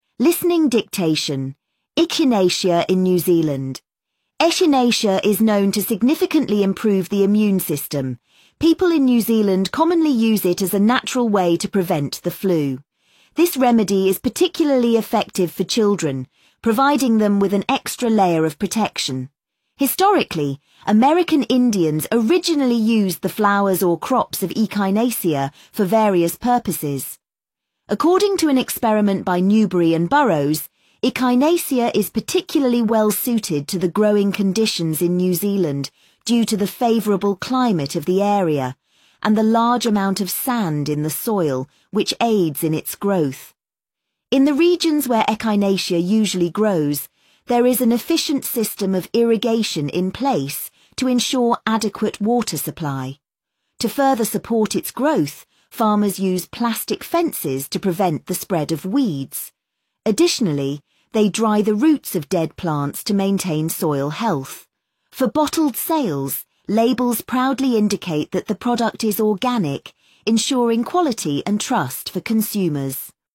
Echinacea in New Zealand IELTS Listening Practice Test